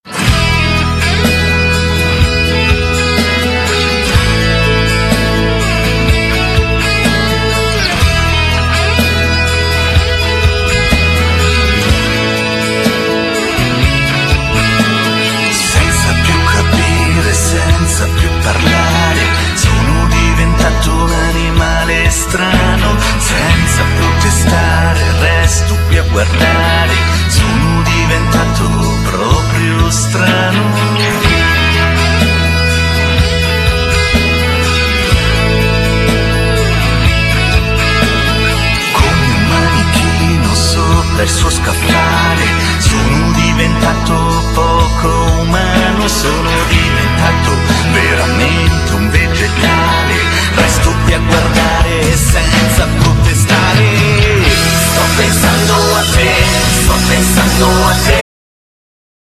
Genere : Pop
un misto tra il lento e l'agitato